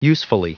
Prononciation du mot usefully en anglais (fichier audio)
Prononciation du mot : usefully